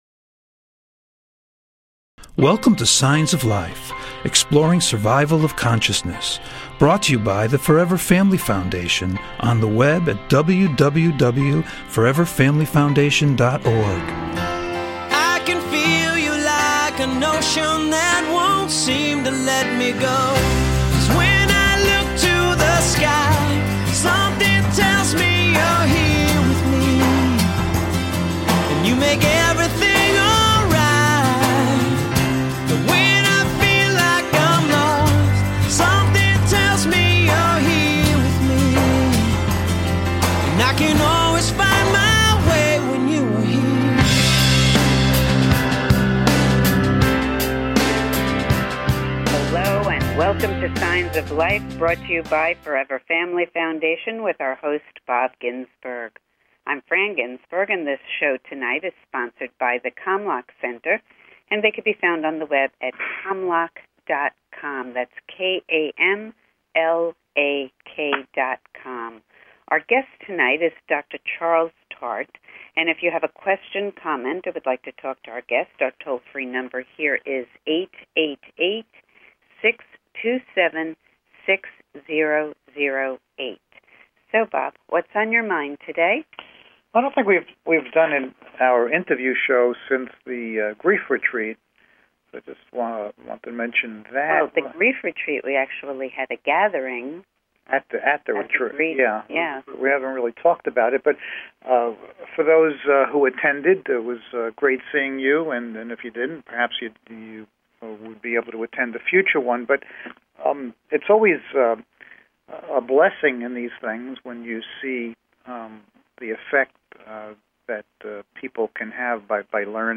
Signs of Life Radio Show is a unique radio show dedicated to the exploration of Life After Death!
Call In or just listen to top Scientists, Mediums, and Researchers discuss their personal work in the field and answer your most perplexing questions.